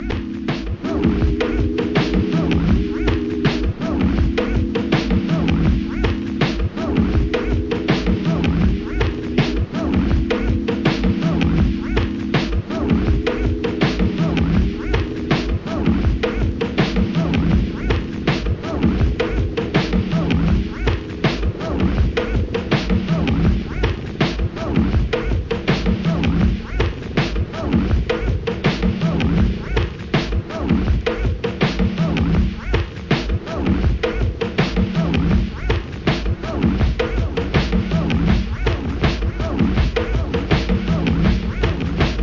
DOWNTENPO,ブレイクビーツ・コンピレーション!!